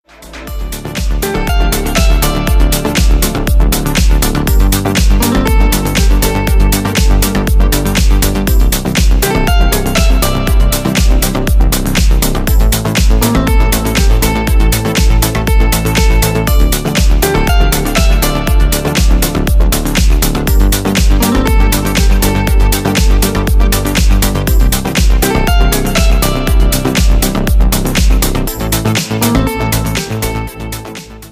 • Качество: 320, Stereo
гитара
remix
атмосферные
Electronic
спокойные
без слов
Стиль: deep house